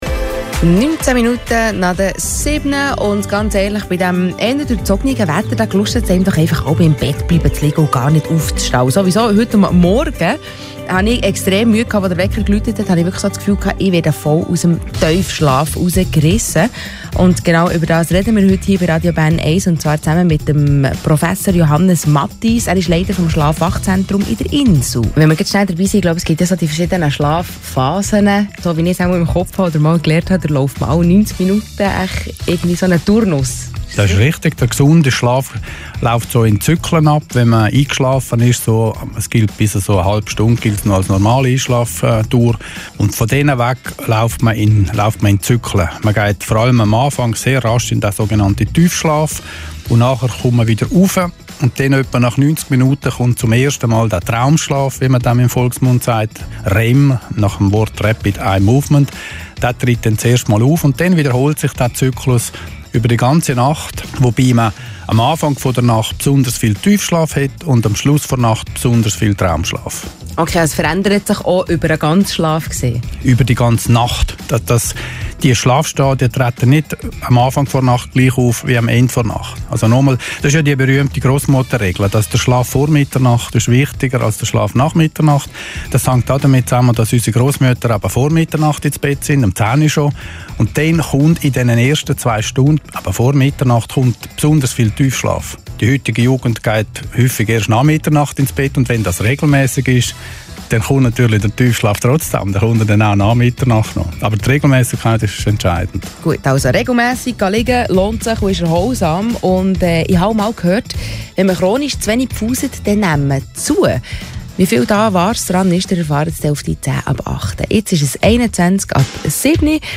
Beiträge vom Radio Bern1, Dienstag, 31. Mai 2016, Morgenshow